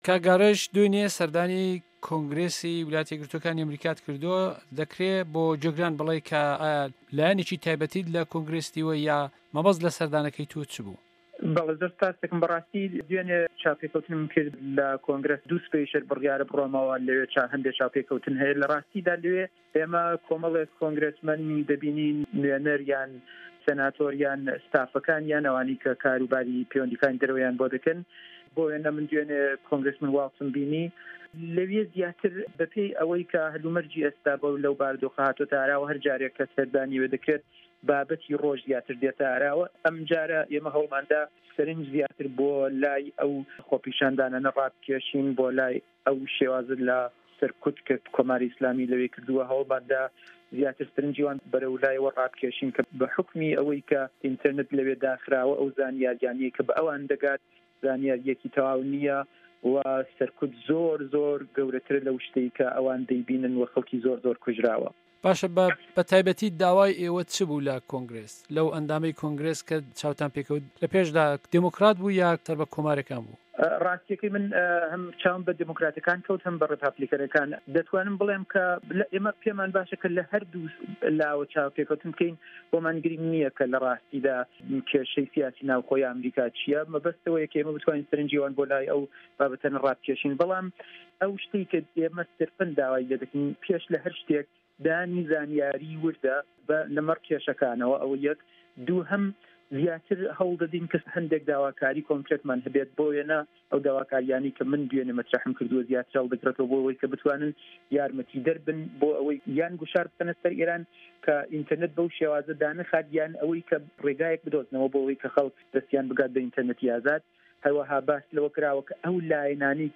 خۆپێشاندانه‌کان له‌ ئێران و کوردستان - وتووێژ